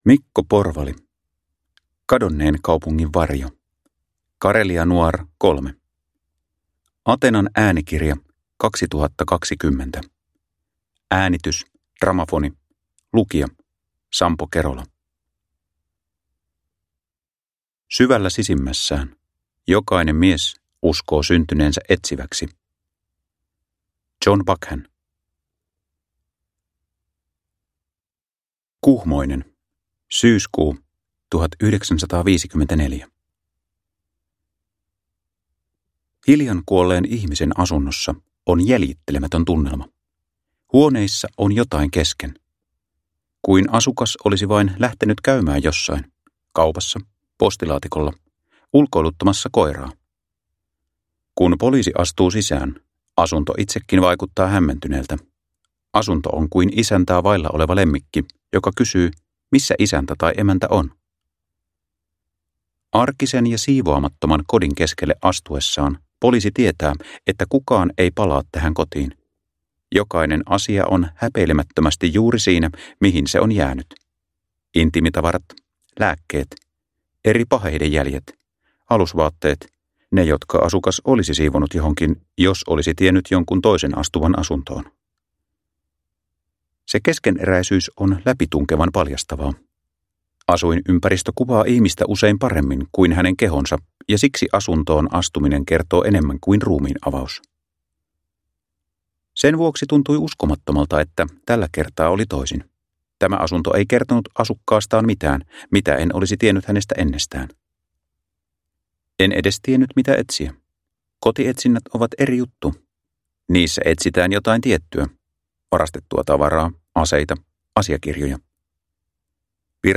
Kadonneen kaupungin varjo – Ljudbok – Laddas ner